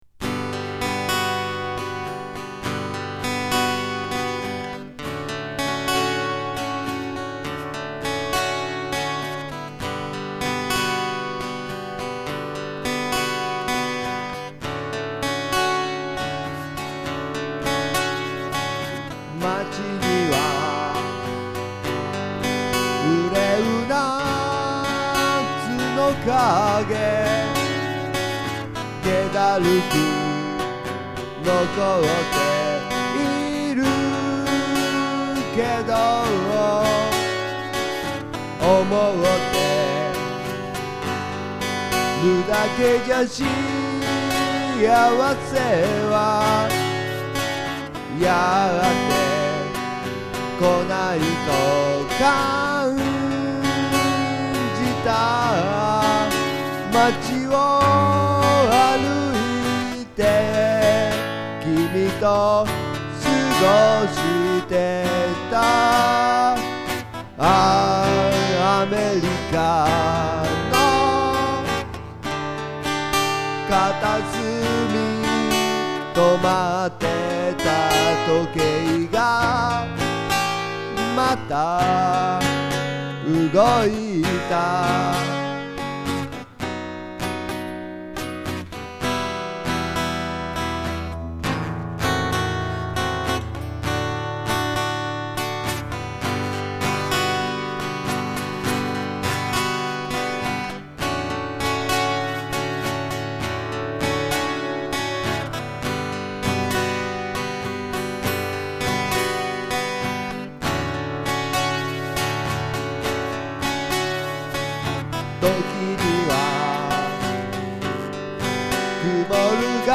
また、昔つくった曲をギターで録音してみた。メジャーセブンスのコードが多いため、今回のは、ちょっと演奏が難しかった。
歌もなかなか、上手に歌えない。コードの内音でない音や、メジャーセブンスの音を歌わなければならないせいだと思うが、なんか音程がフラフラしてしまう。いつもにもまして、歌の部分がよくない感じ。。。
今回は、ギターを２つ重ねてみた。